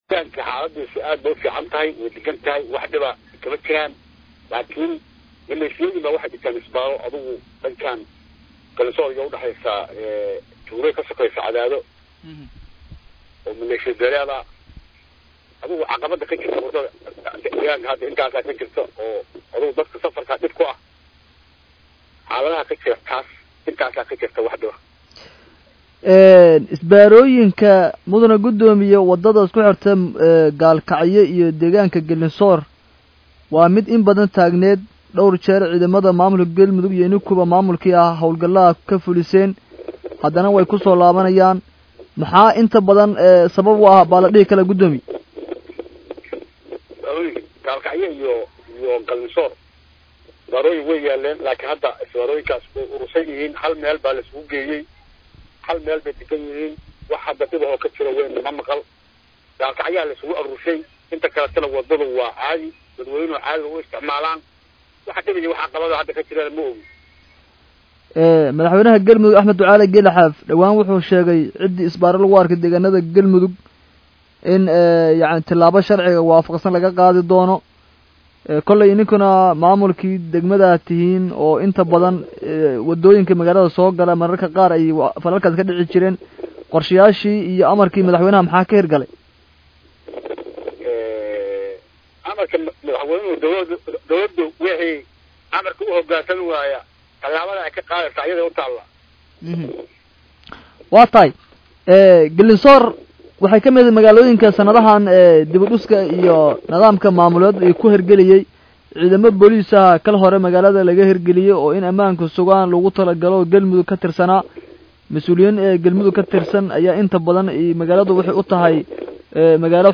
Guddoomiyaha Degmada Galiinsoor ee Gobolka Galgaduud ee Galmudug Xirsi Muuse Maxamed oo la hadlay Radio Muqdisho Codka Jamhuuriyadda Soomaaliya ayaa
wareysai-Gudoomioyaha-Degmada-Galiinsoor-Xirsi-Muuse-Maxamed.mp3